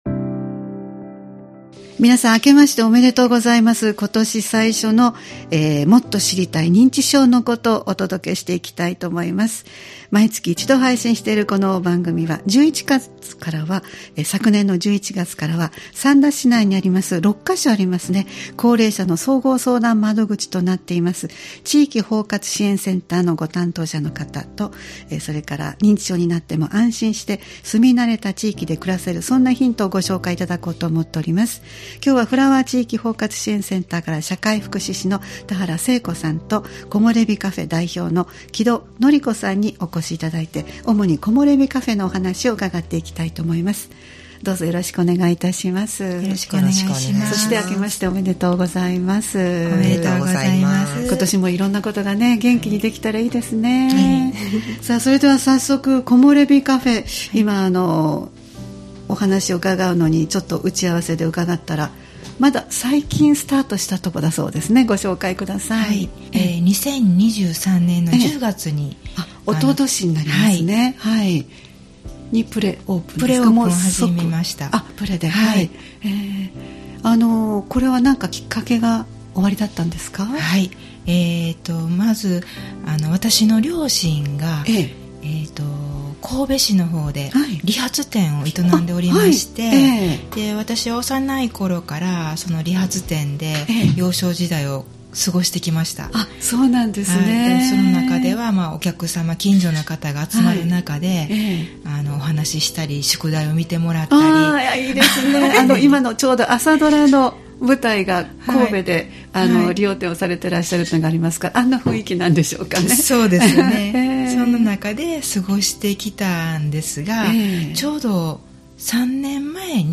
毎月第1月曜日に配信するポッドキャスト番組「もっと知りたい認知症のこと」 スタジオに専門の方をお迎えして、認知症に関連した情報、認知症予防の情報、介護や福祉サービスなどを紹介していただきます（再生ボタン▶を押すと番組が始まります）